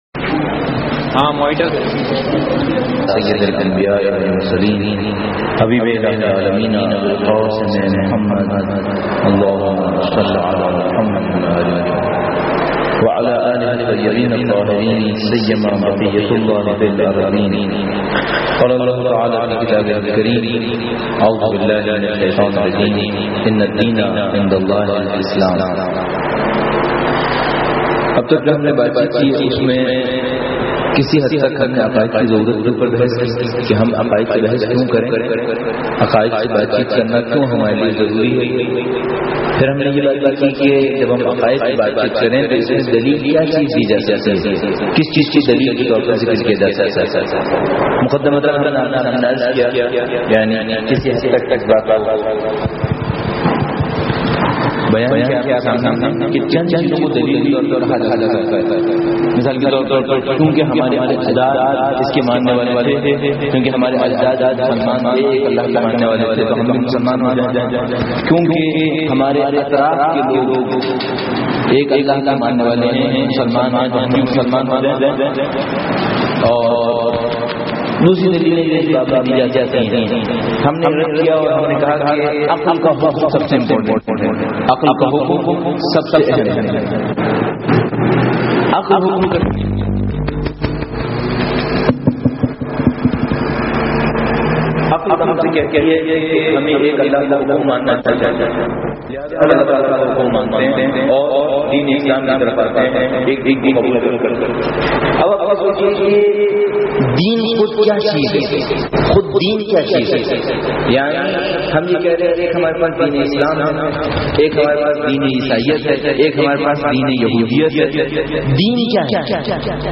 درس عقائد_3